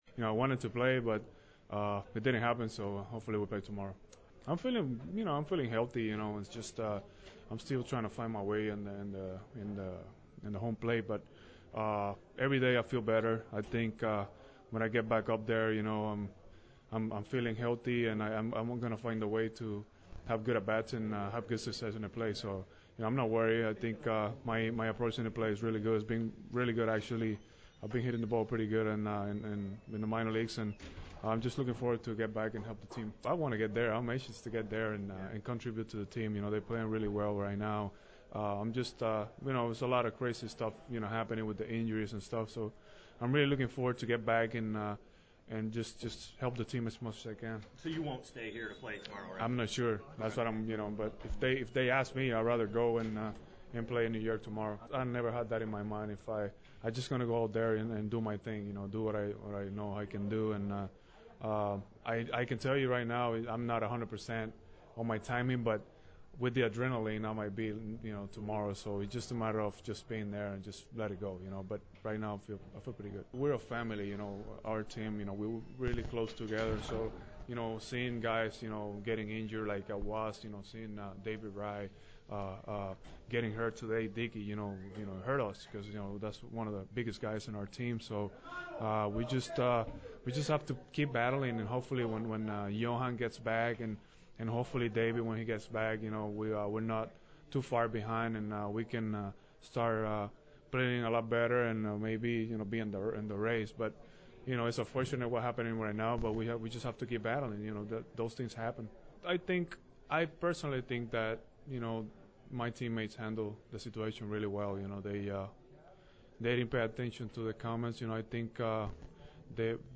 LISTEN: Angel Pagan answered questions from the media: